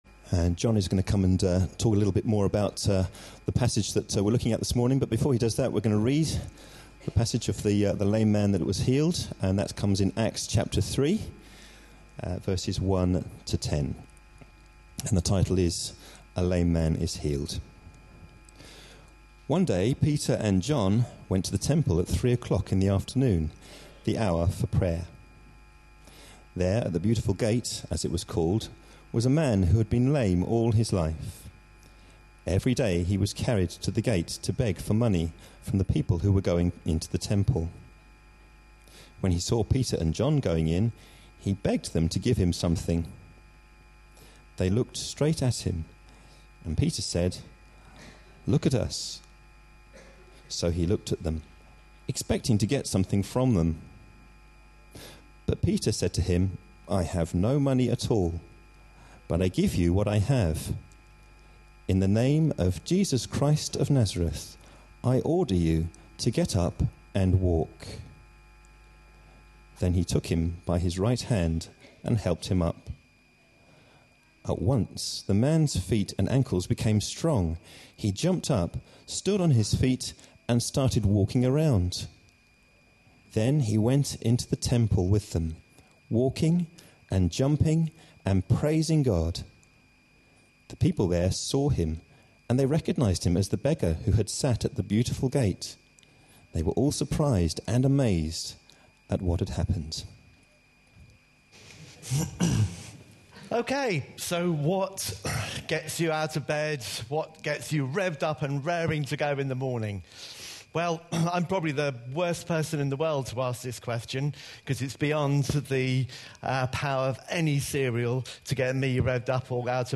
A sermon preached on 21st April, 2013.
This talk started with a TV commercial for a popular breakfast cereal (not included in the recording), and earlier in the service, a film dramatisation was shown of the lame man being healed.